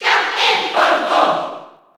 File:Captain Falcon Cheer JP SSB4.ogg
Captain_Falcon_Cheer_JP_SSB4.ogg